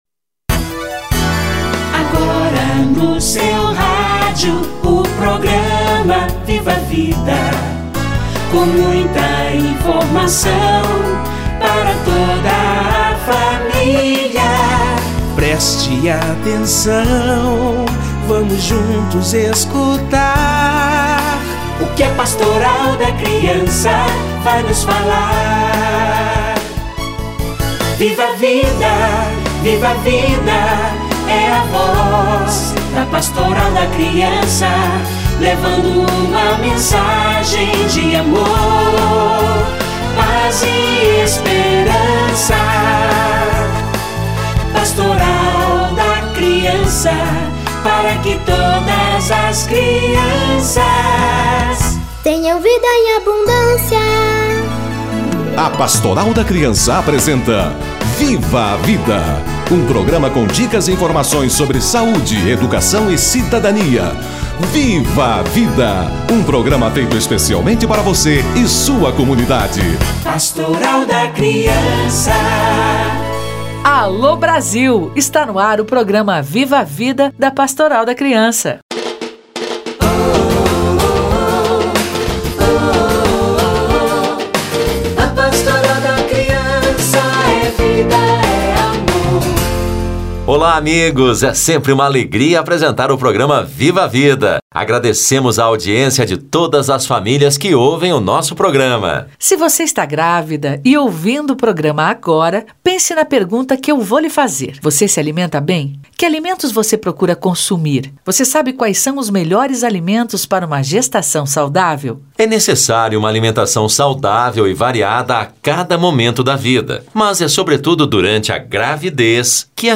Alimentação da gestante - Entrevista